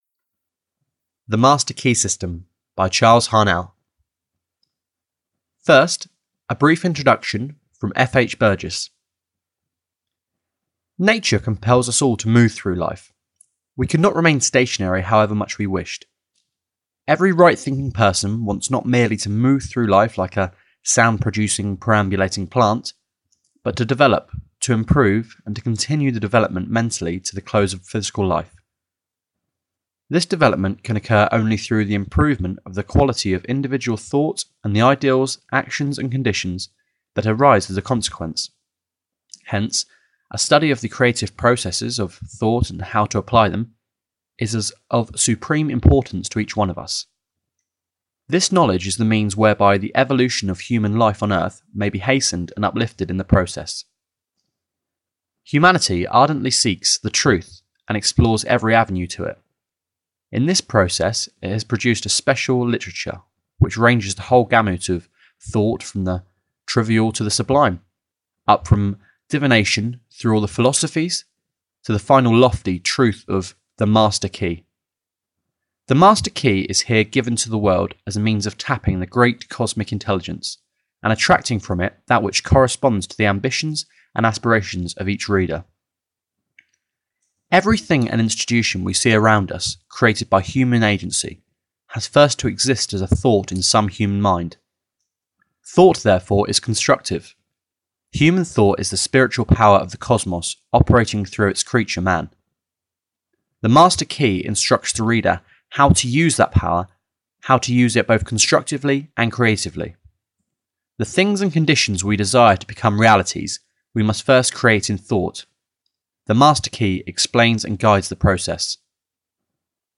The Master Key System (EN) audiokniha
Ukázka z knihy